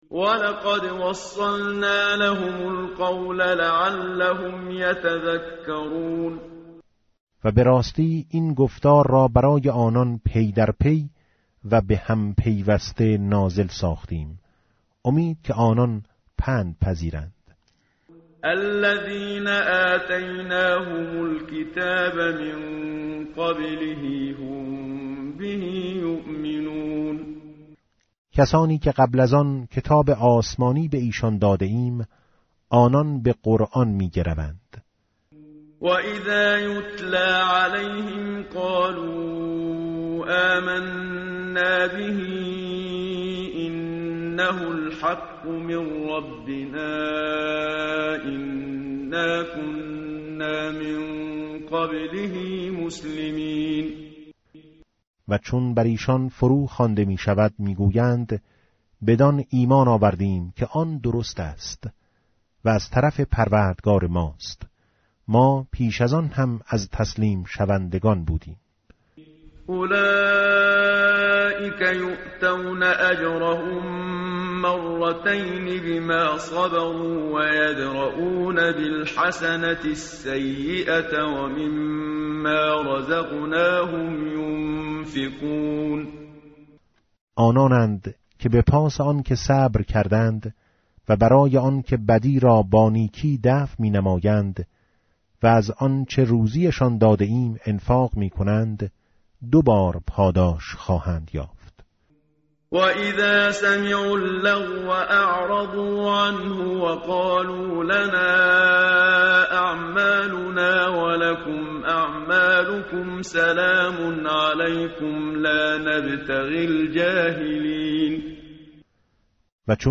tartil_menshavi va tarjome_Page_392.mp3